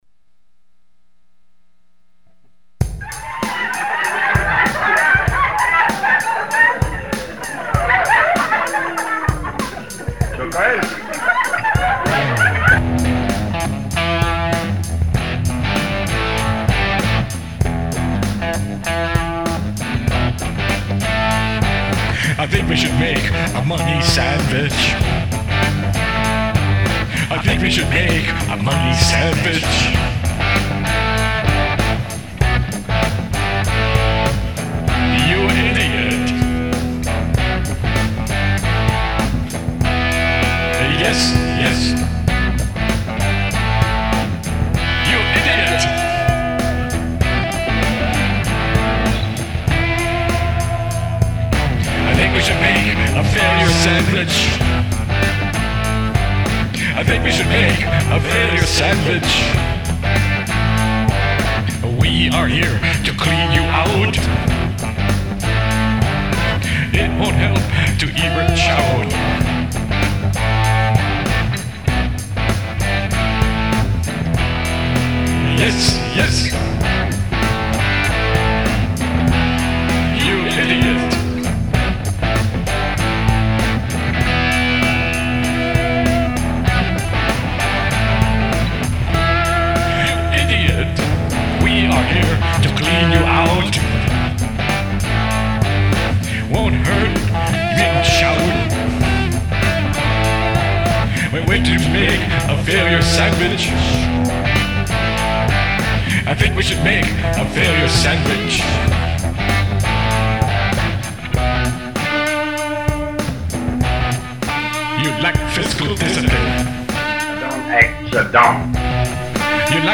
Rock 'n' Roll